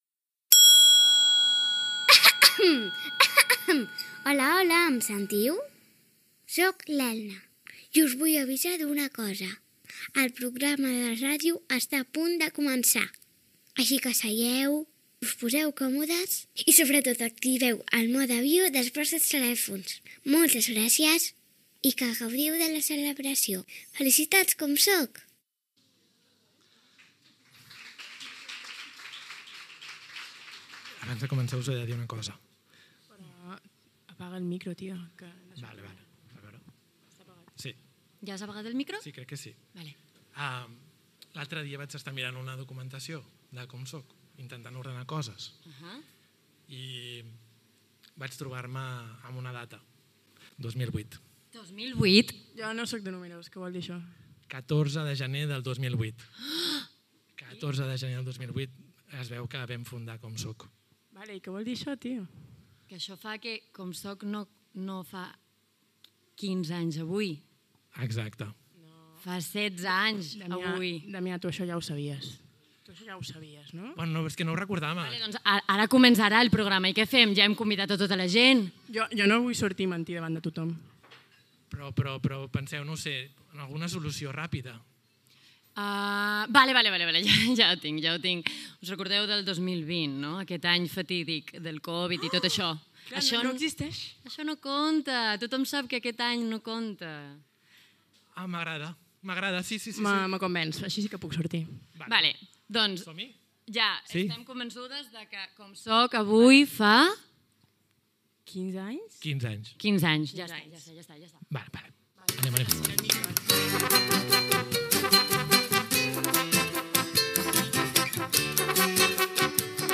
Careta del programa, presentació recordant la fundació de COMSOC l'any 2008, sintonia, benvinguda, equip, agraïments, sumari, dades de COMSOC, felicitacions sonores
Espai fet cara al públic al bar cultural La Iguana de Sants, Barcelona